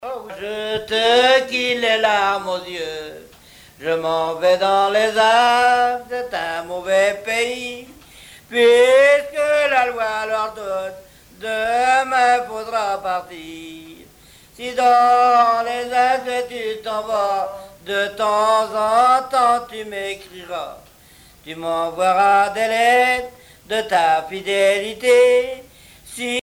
Genre strophique
Chansons traditionnelles